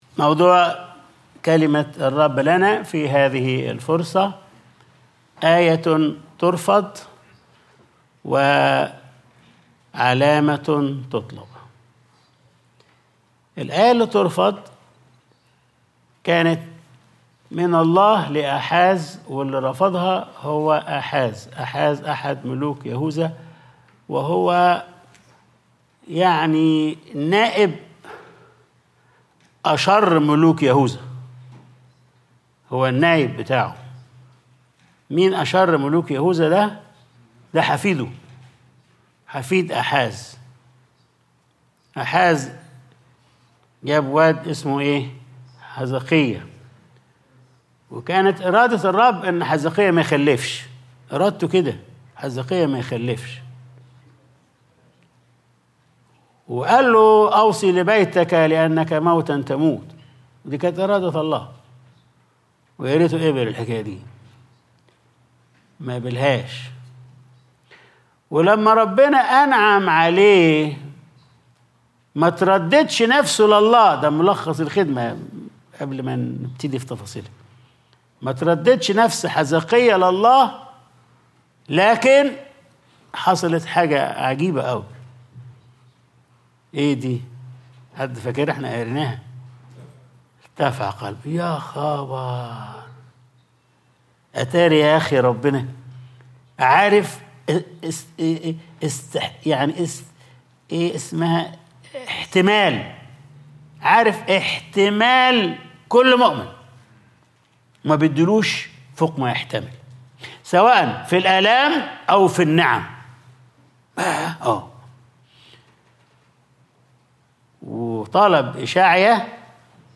Sunday Service | آية تُرفَض وعلامة تُطلَب